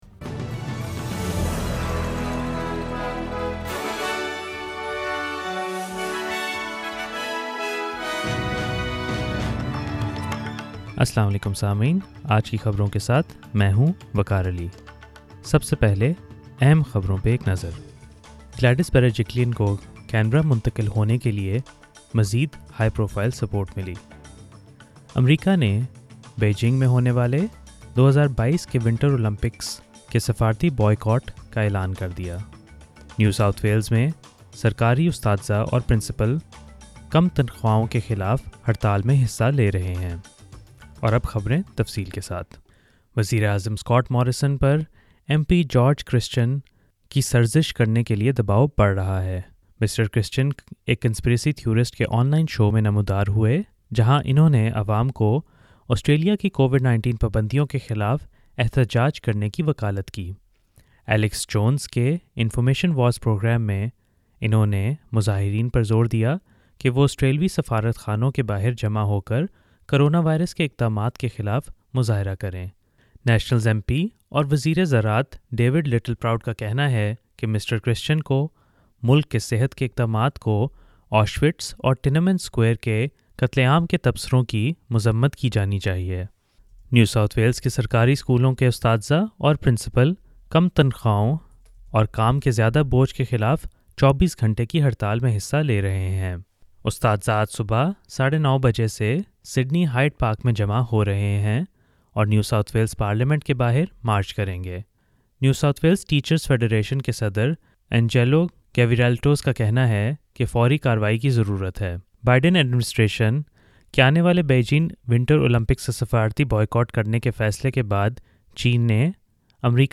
SBS Urdu News 7 December 2021